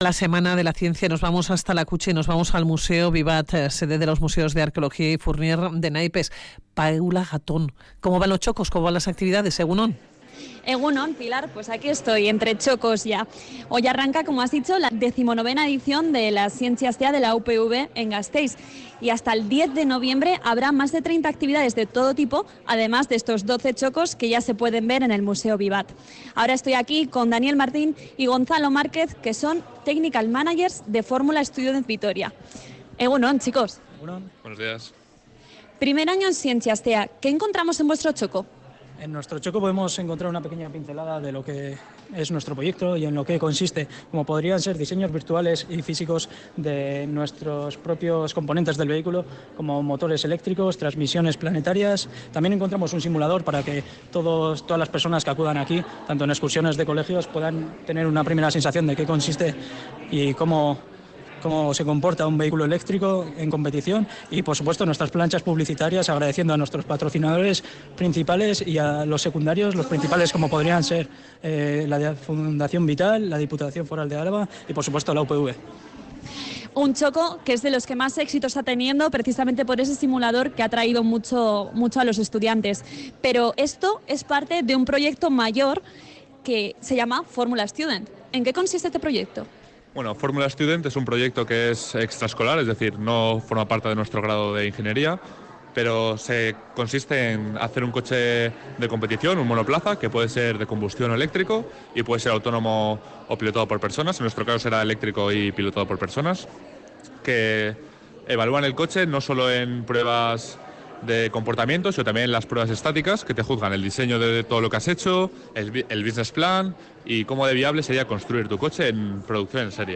Audio: Nuestra unidad móvil se ha acercado al museo Bibat, en el Casco Viejo, con motivo de la Zientzia Astea que empieza hoy con más de 30 actividades y 12 tokos.